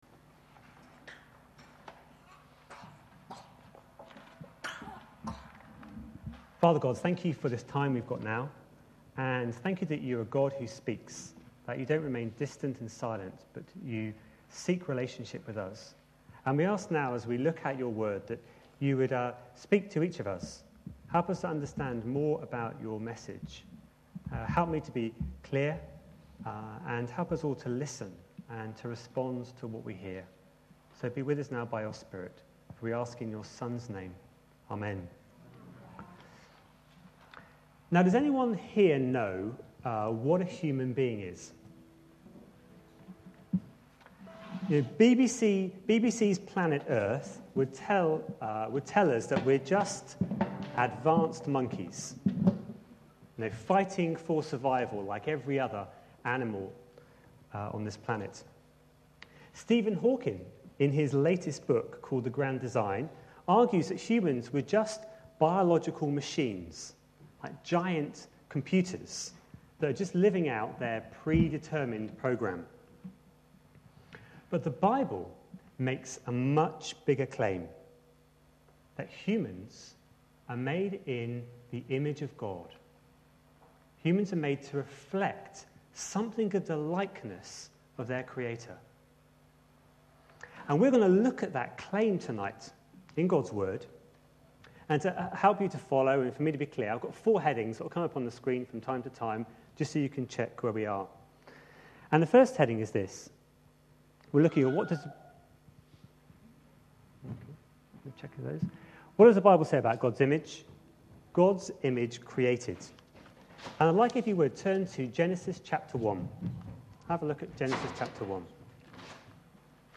A sermon preached on 13th March, 2011, as part of our What does the Bible say about... series.